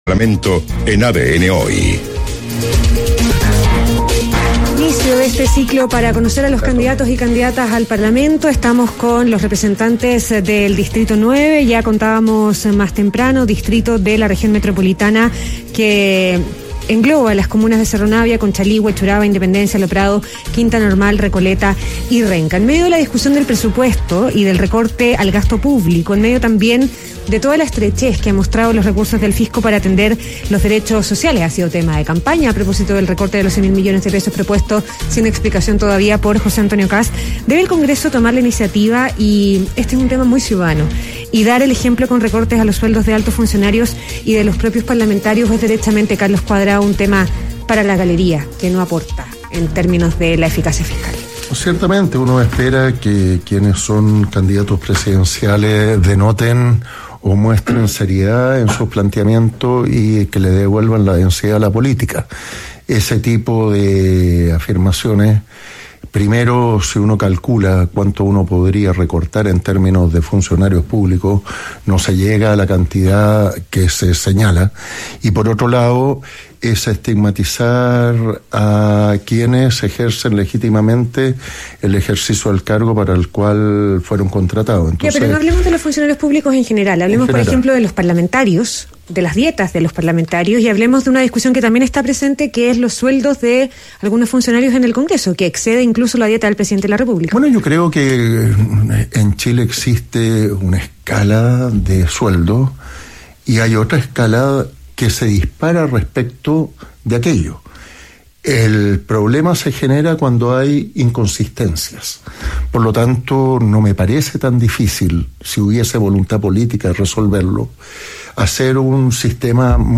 ADN Hoy - Primer debate parlamentario